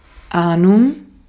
Ääntäminen
Synonyymit concept construct Ääntäminen US : IPA : [ˈnoʊ.ʃən] Haettu sana löytyi näillä lähdekielillä: englanti Käännös Konteksti Ääninäyte Substantiivit 1.